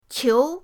qiu2.mp3